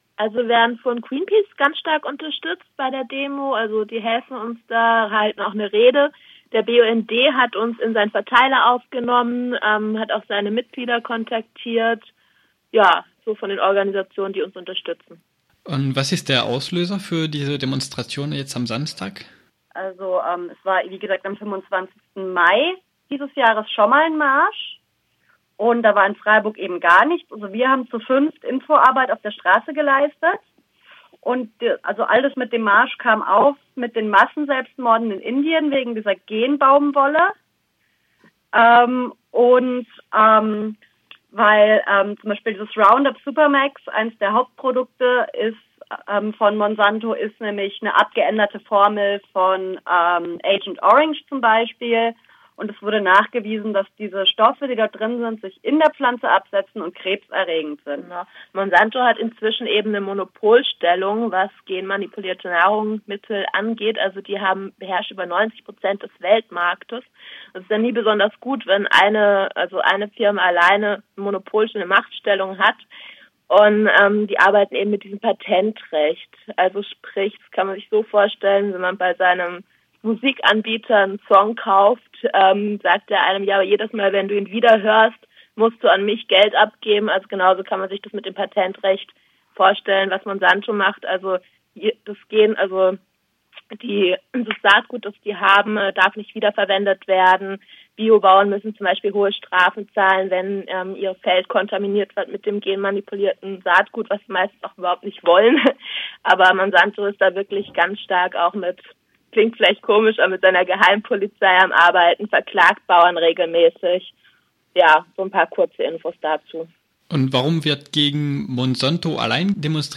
21.10.2013 Ein Bericht mit O-Tönen aus der antimilitaristischen Fahrraddemo, die am Samstag, 19.10. in Freiburg stattfand. Veranstaltet wurde die Demo vom Arbeitskreis gegen Krieg und Militarisierung (AKM).